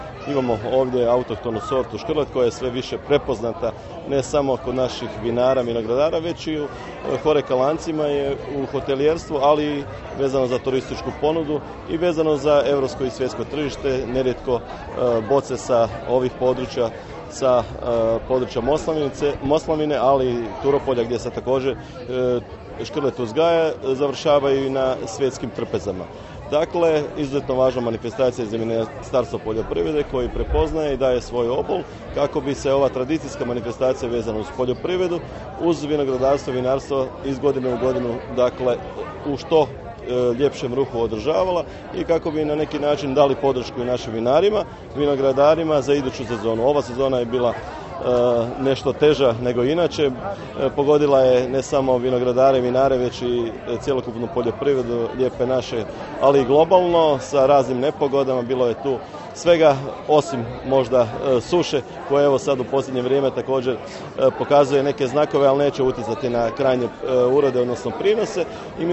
Ovo je važan vinarski i vinogradarski kraj, u njemu, službeno imamo zasađeno oko 70-ak hektara autohtone sorte škrleta, rekao je u Voloderu izaslanik predsjednika Vlade RH, državni tajnik Ministarstva poljoprivrede Tugomir Majdak